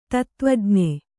♪ tattvajn`e